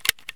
ammo_load5.ogg